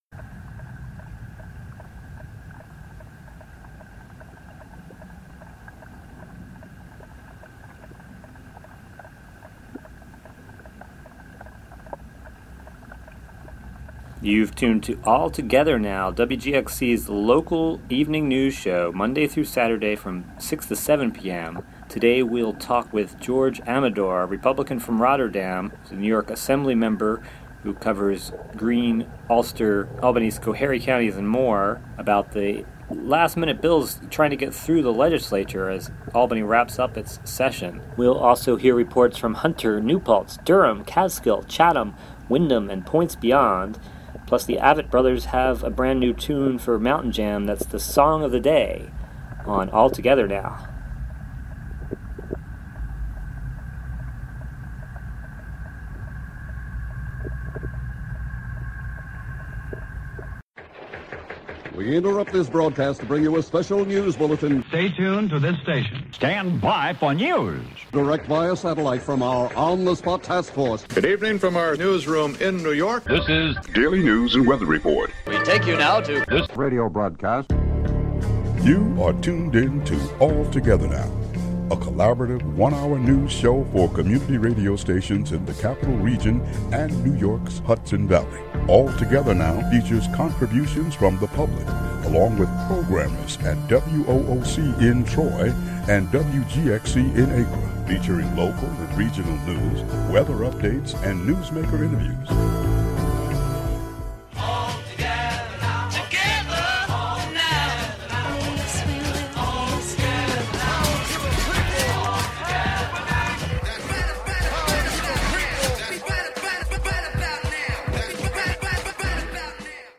State Senator George Amedore calls in to discuss several bills in the legislature and the chance for ethics reform. There are also several reports from Albany as the legislature nears its end, and other reports from Hunter, New Paltz, Durham, Catskill, Chatham, Windham and points beyond.